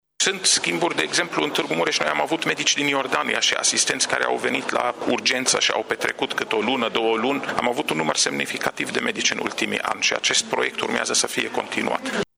Medicii iordanieni vin la Tg.Mureș pentru schimb de experiență în medicina de urgență, iar această colaborare se va intensifica, a declarat secretarul de stat în MAI, Raed Arafat, într-un interviu